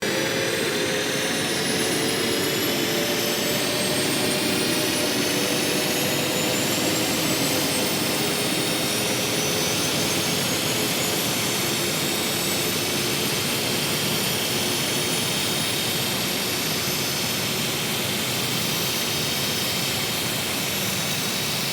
Free SFX sound effect: Turbine Idle.
Turbine Idle
Turbine Idle.mp3